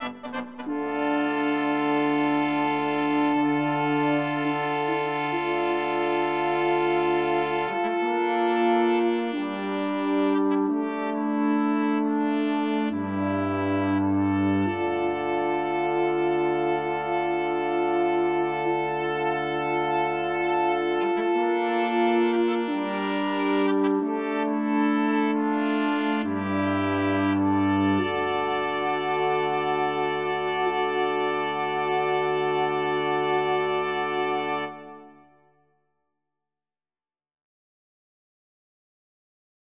Coronet and VSTi French Horn